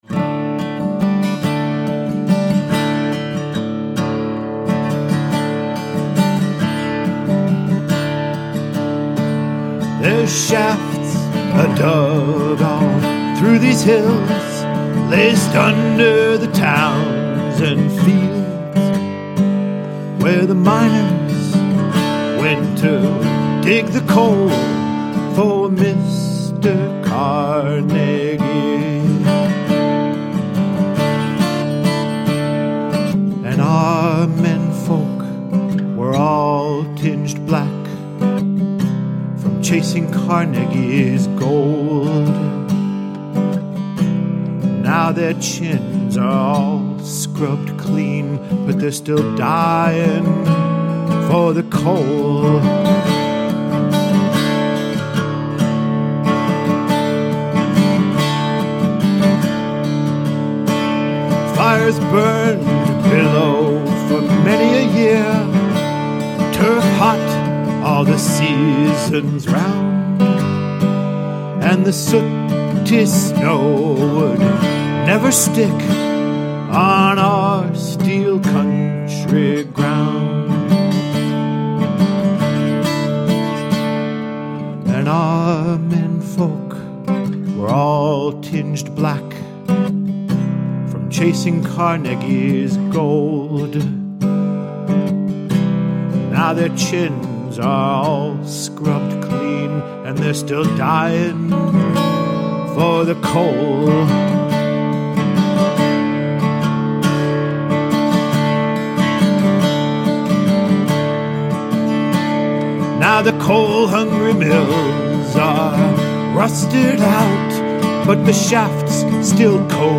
Double Drop-D.